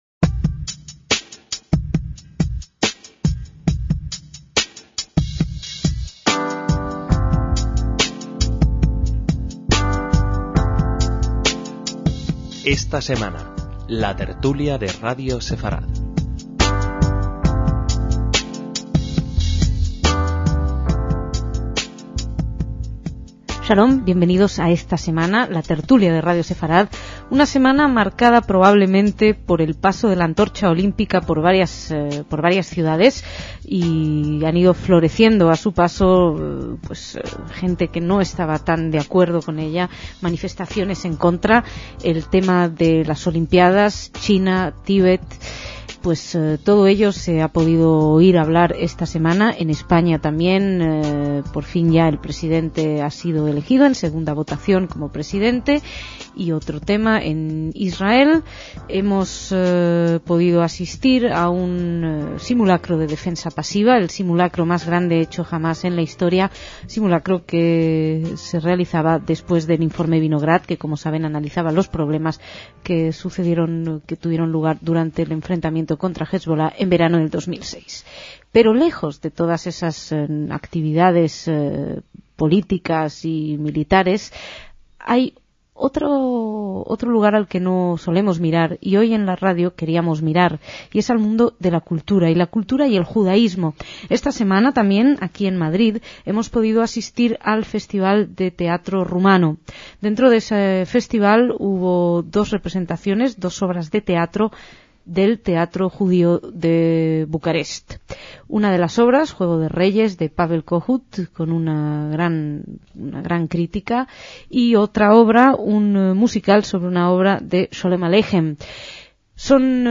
Esta tertulia no tuvo invitados externos, sino que se plasmó con dos de los colaboradores de la radio en temas culturales: